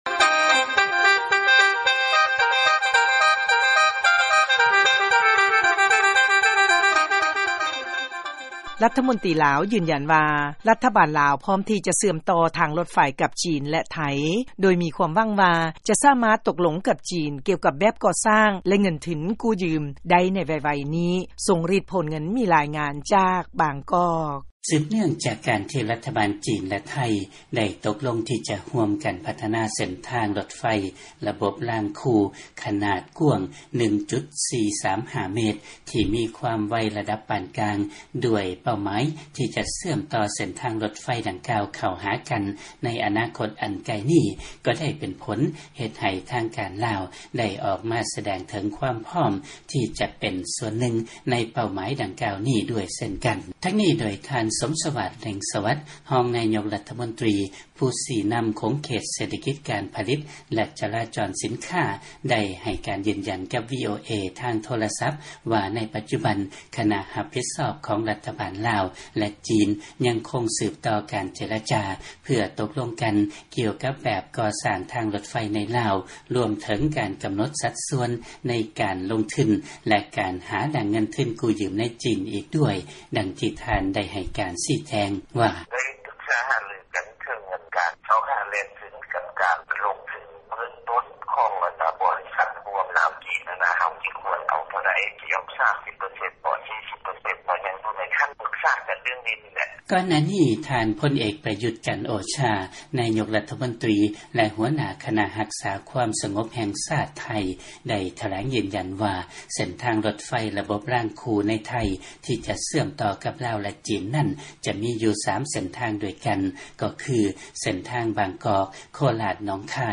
ທັງນີ້ ໂດຍ ທ່ານ ສົມສະຫວາດ ເລັ່ງສະຫວັດ ຮອງນາຍົກລັດຖະມົນຕີ ຜູ້ຊີ້ນຳຂົງເຂດເສດຖະກິດ ການຜະລິດ ແລະຈະລາຈອນສິນຄ້າ ໄດ້ໃຫ້ການຢືນຢັນກັບ ວີໂອເອ ທາງ